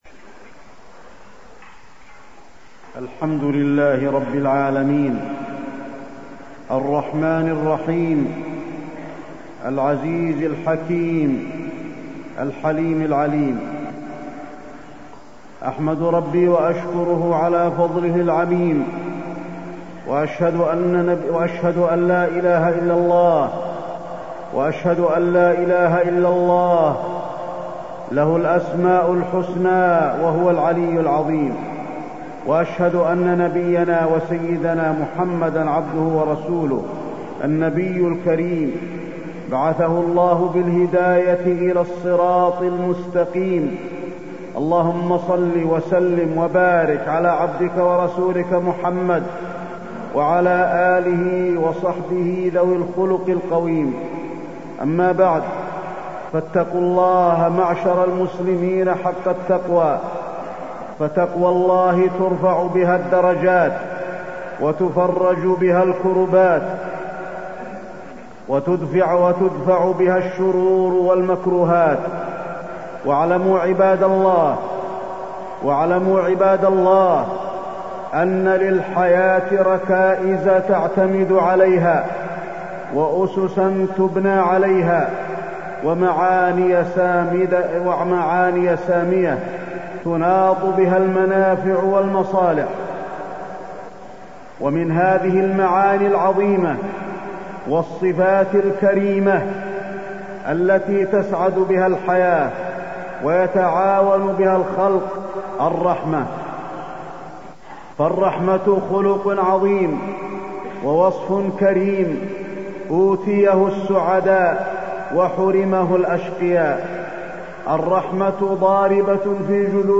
تاريخ النشر ١٠ ذو القعدة ١٤٢٤ هـ المكان: المسجد النبوي الشيخ: فضيلة الشيخ د. علي بن عبدالرحمن الحذيفي فضيلة الشيخ د. علي بن عبدالرحمن الحذيفي الرحمة